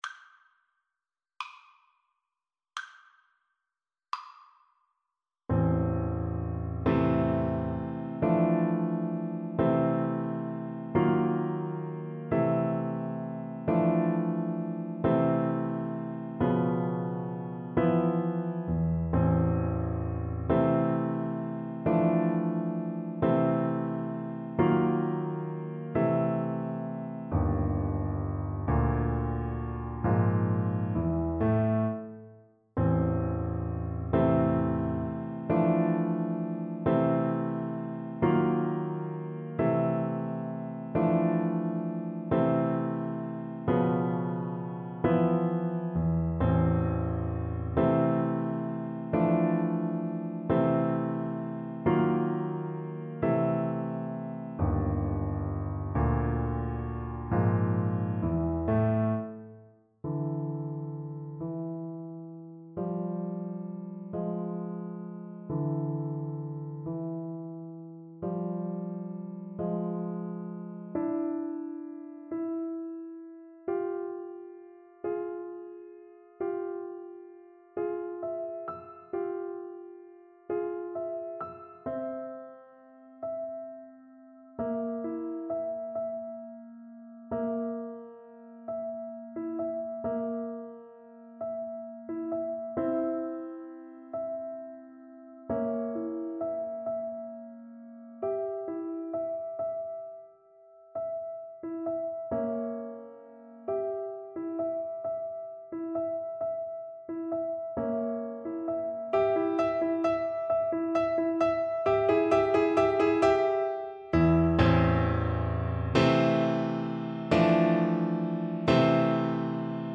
6/8 (View more 6/8 Music)
French Horn  (View more Intermediate French Horn Music)
Classical (View more Classical French Horn Music)
film (View more film French Horn Music)